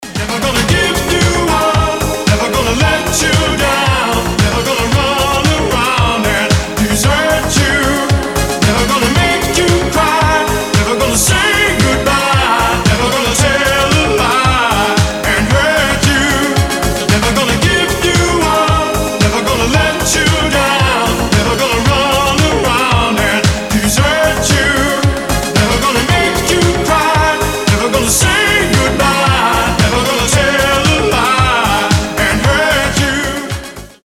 • Качество: 320, Stereo
спокойные
Dance Pop
дискотека 80-х